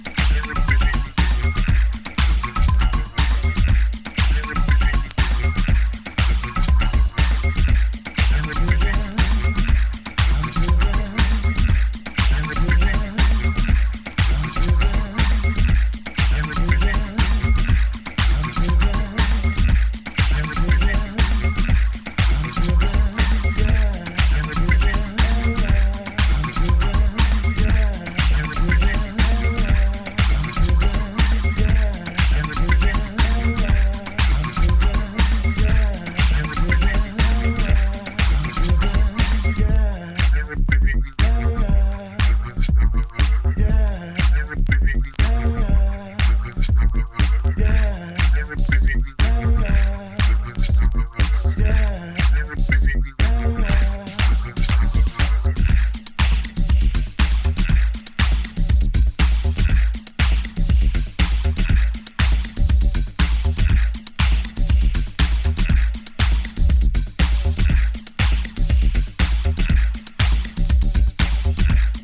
Acidだったり様々な要素が混ざりあった過渡期のハウス。
ジプシー民謡のホーンのワンループに、シンプルなビート、思い出したかのようにたまに現れる別のホーンフレーズ、、、、。
浮遊するキーボードにグルーヴィンなベースラインがたまらない